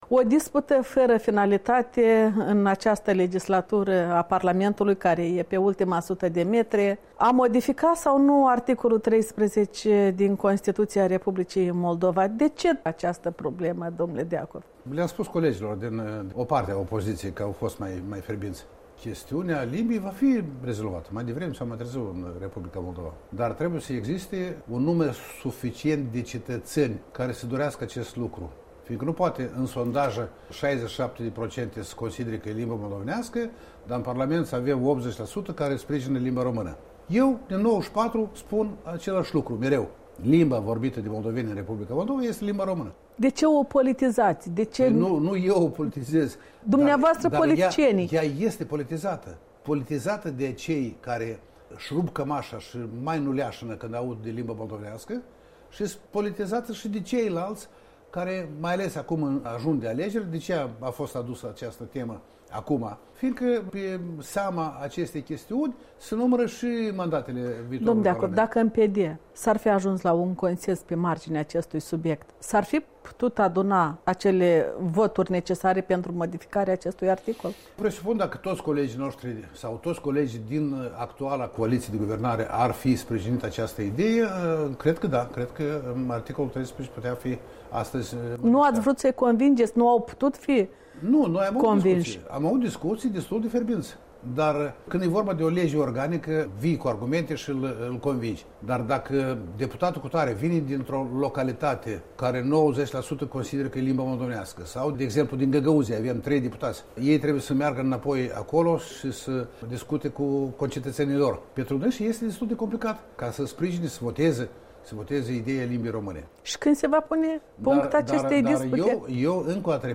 Un interviu cu președintele de onoare al Partidului Democrat.